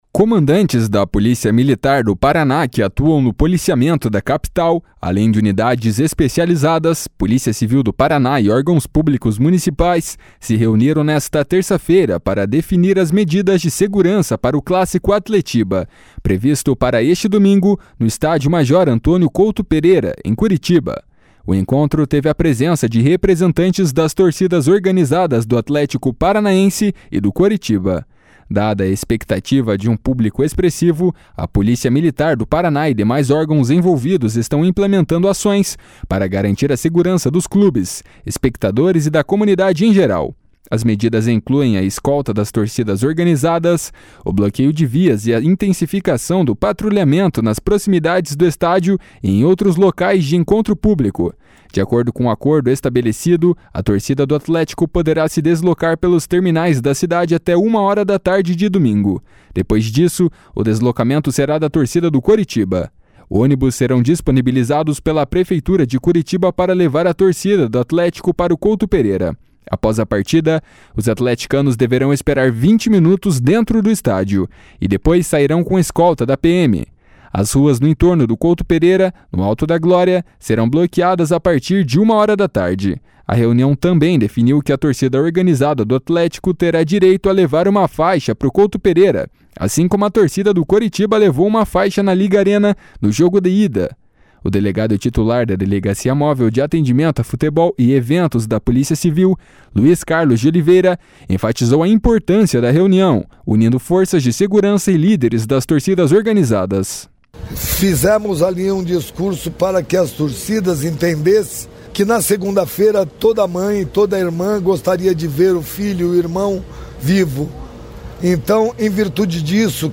A reunião teve a participação de representantes de companhias e batalhões da PM e da Guarda Municipal.